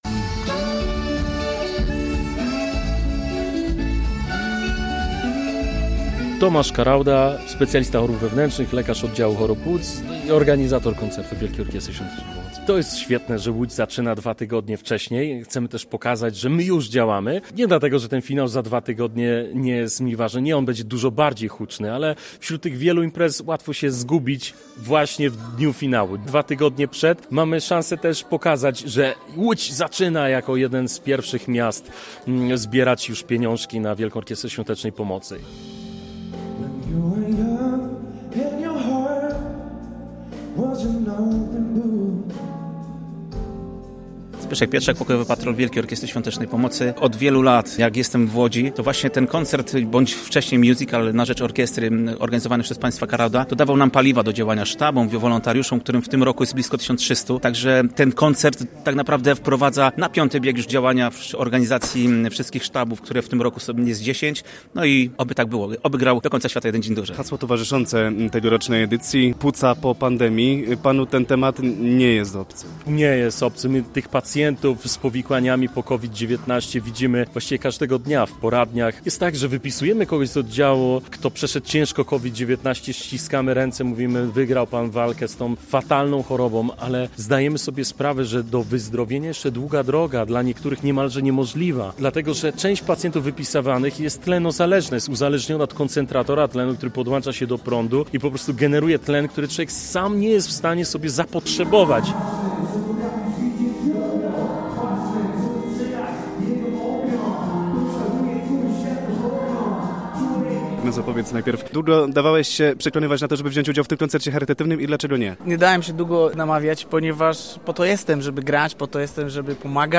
W łódzkim klubie Wytwórnia odbył się 10. koncert charytatywny Wielkiej Orkiestry Świątecznej Pomocy.
Przy muzyce musicalowej, filmowej oraz gospel bawiło się ponad tysiąc osób, a warunkiem udziału w wydarzeniu było wrzucenie pieniędzy do orkiestrowej puszki.